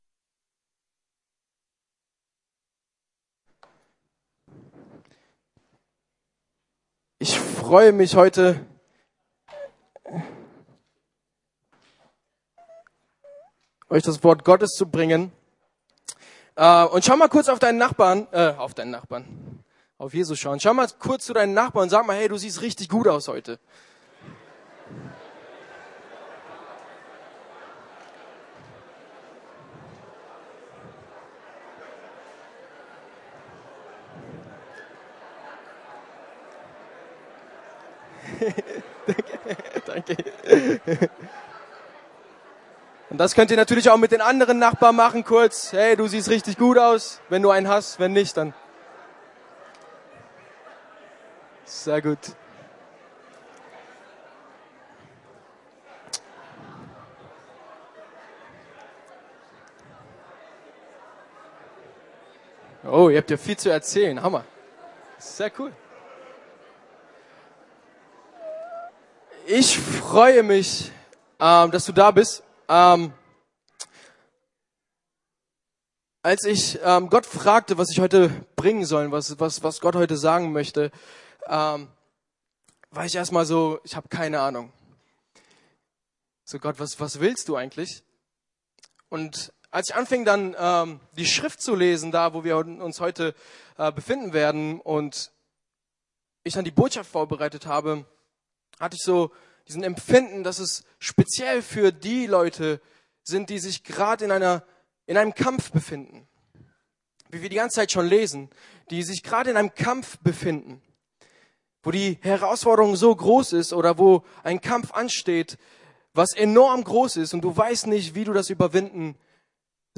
Die Position bestimmt deinen Sieg ~ Predigten der LUKAS GEMEINDE Podcast